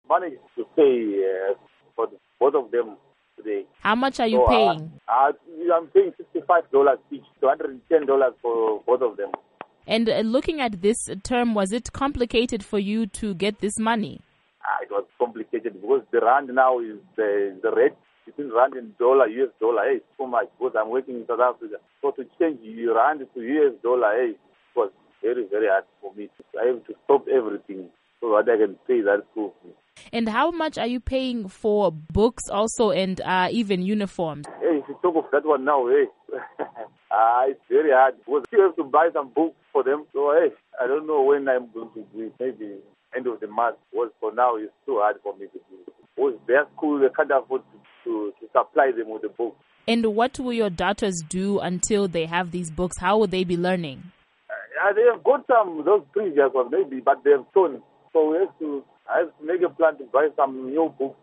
Interview With Zimbabwean Parent